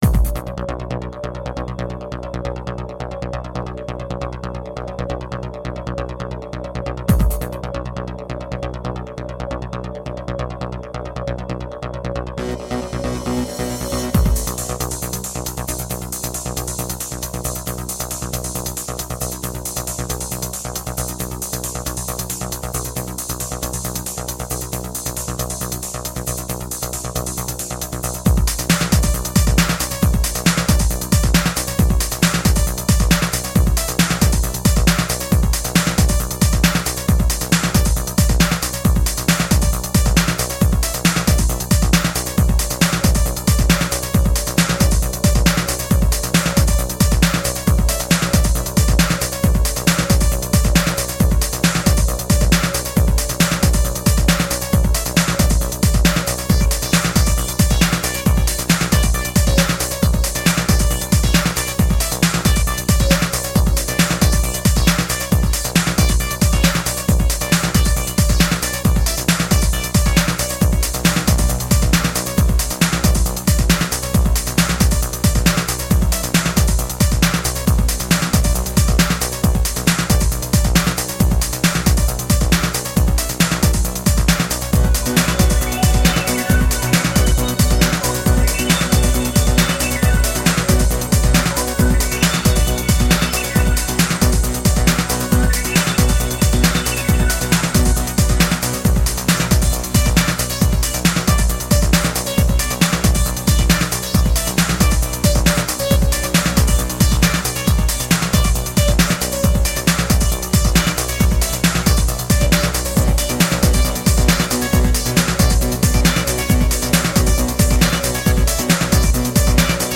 Sublime and ethereal electronic music.
Tagged as: Electronica, Techno